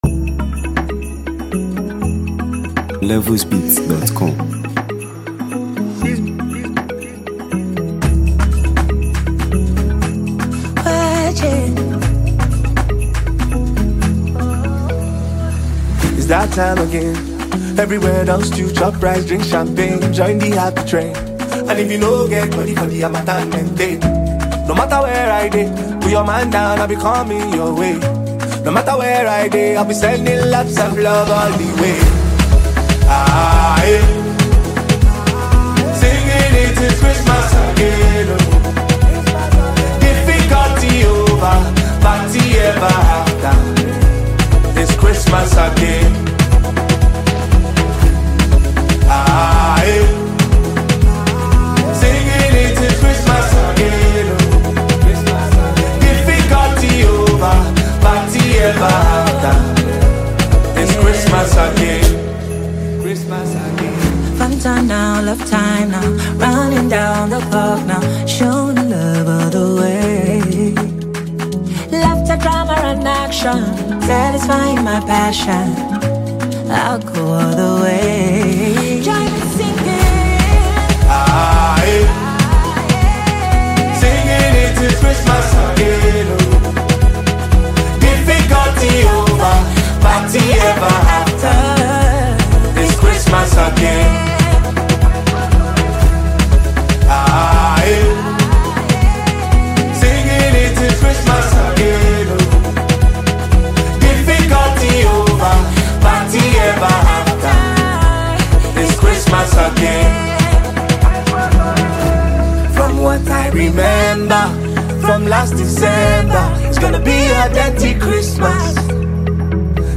heartwarming festive record
powerhouse vocalist
With its feel-good vibe and seasonal message